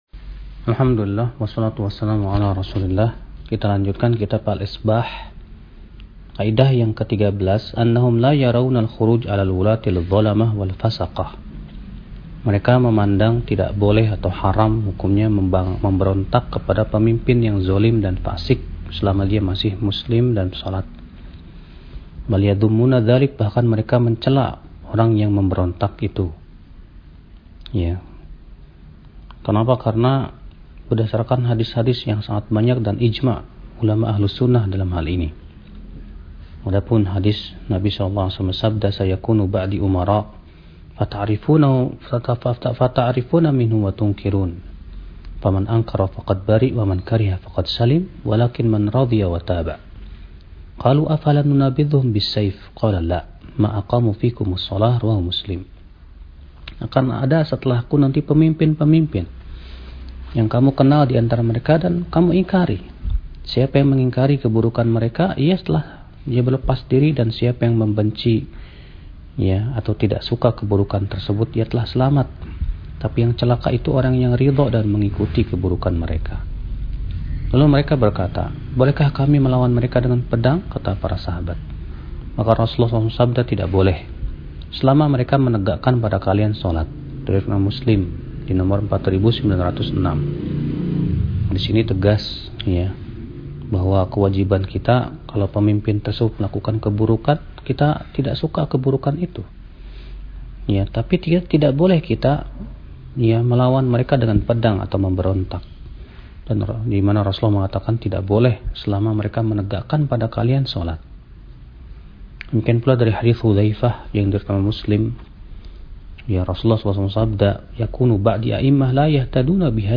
Kajian Audio